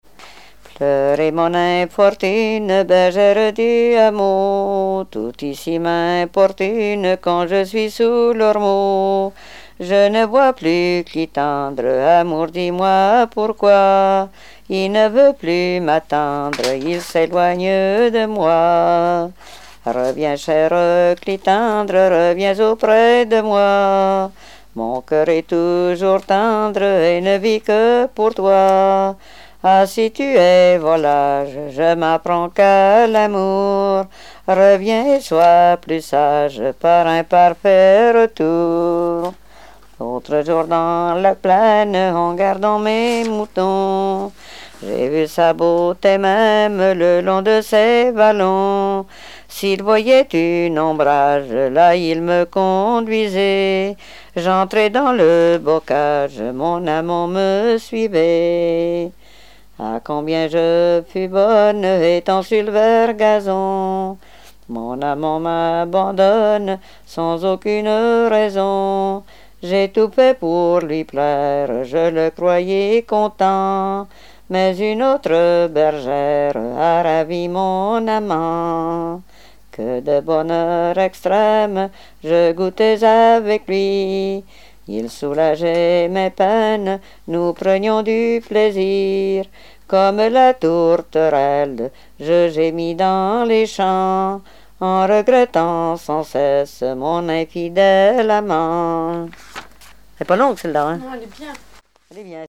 Genre strophique
Répertoire de chansons traditionnelles et populaires